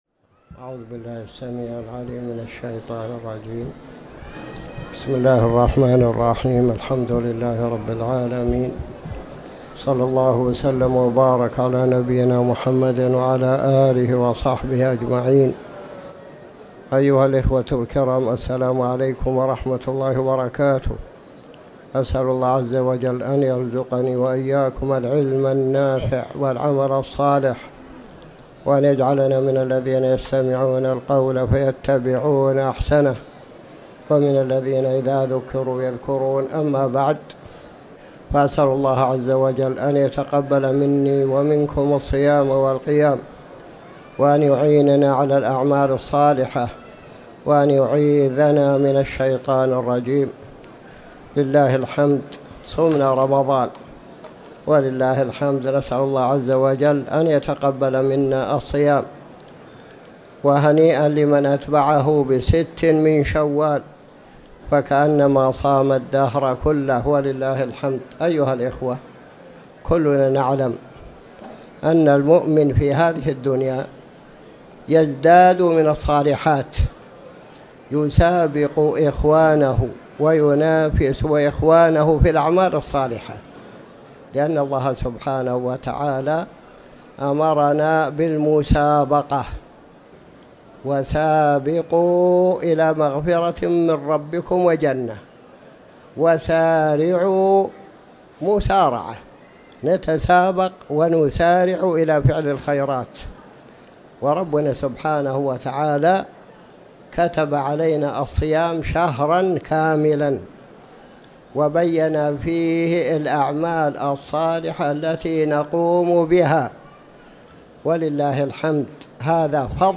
تاريخ النشر ١٩ شوال ١٤٤٠ هـ المكان: المسجد الحرام الشيخ